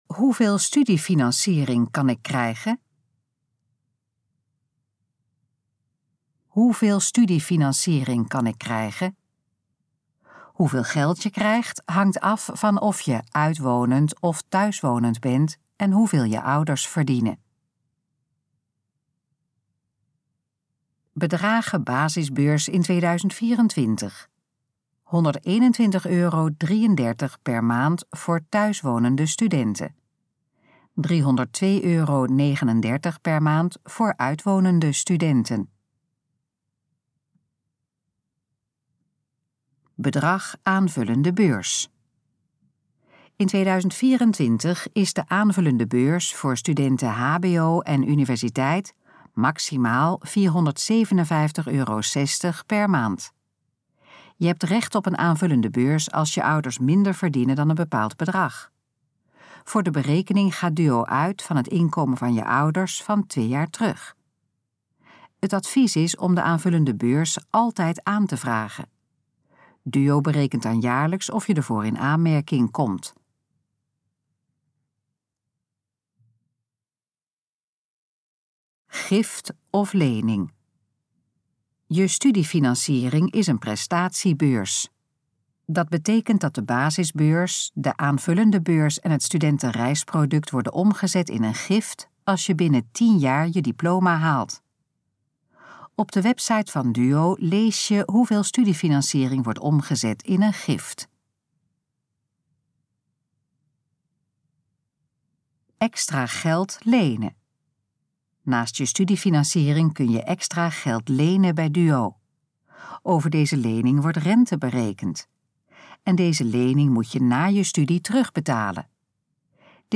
Gesproken versie van: Hoeveel studiefinanciering kan ik krijgen?
Dit geluidsfragment is de gesproken versie van de pagina: Hoeveel studiefinanciering kan ik krijgen?